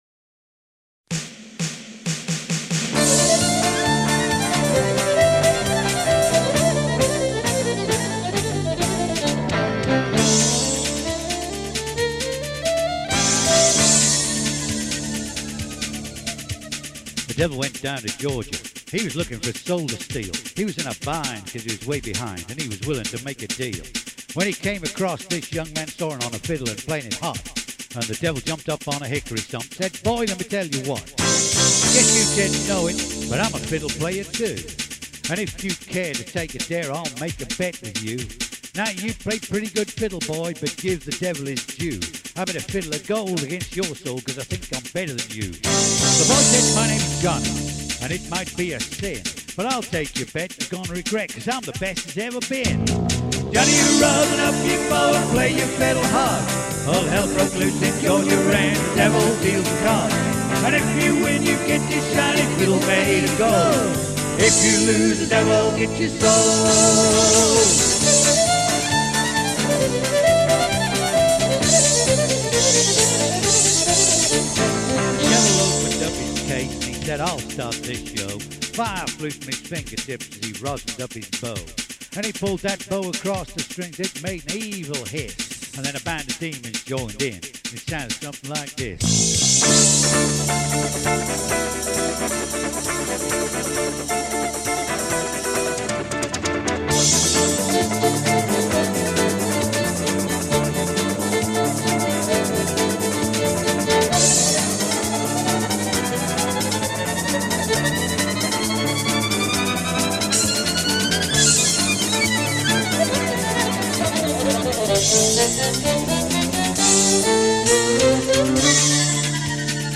Country & Barn dance band